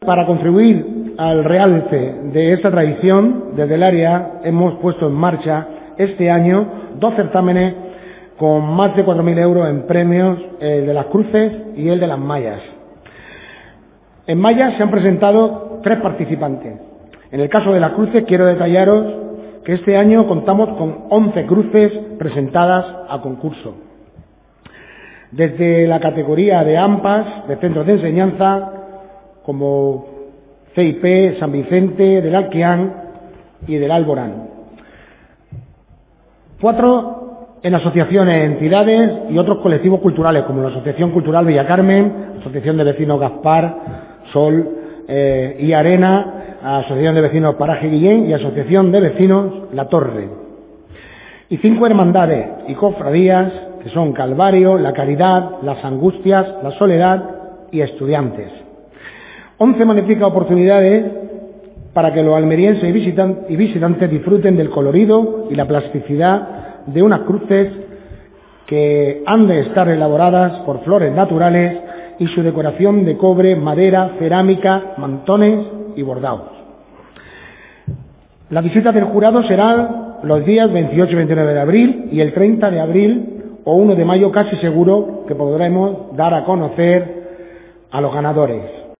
Concejal de Cultura, Diego Cruz